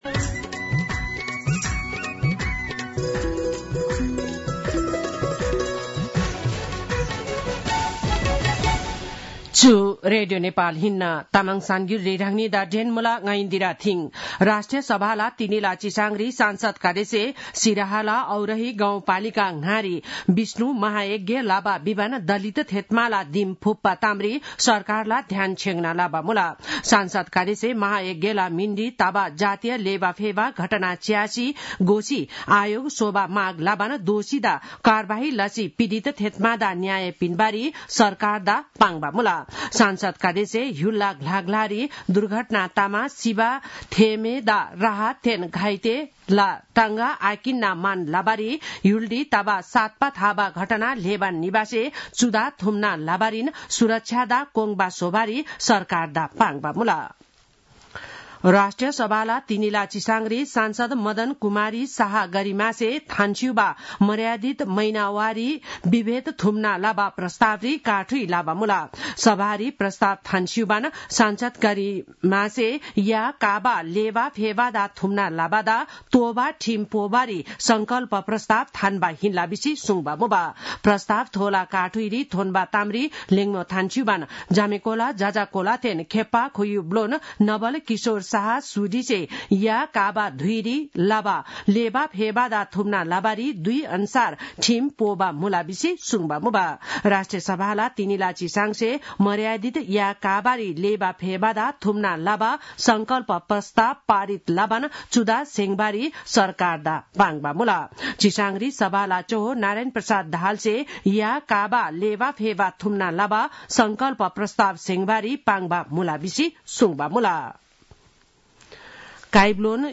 तामाङ भाषाको समाचार : ८ चैत , २०८१